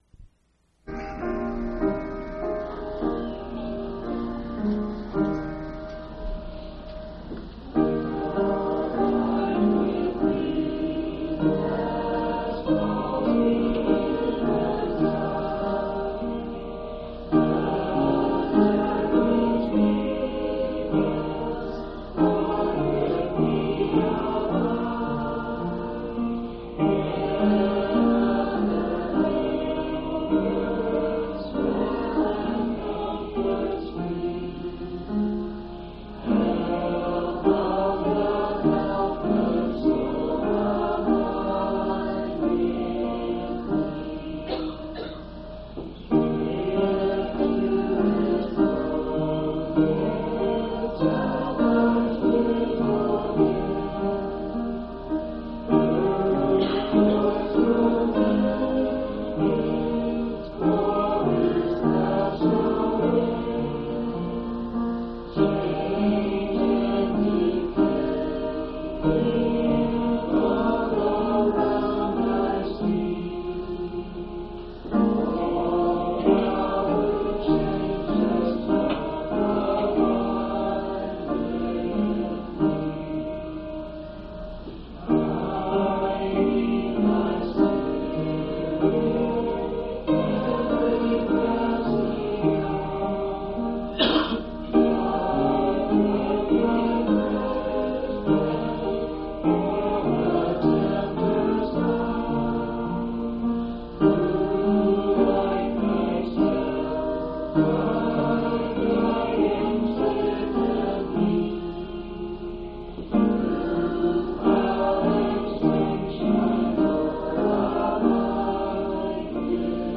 8/12/1990 Location: Phoenix Local Event